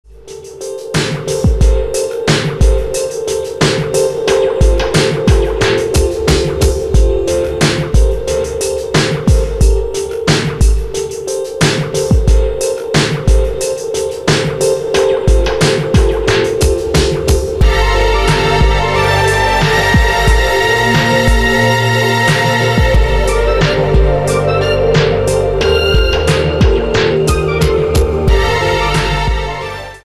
File Under: dub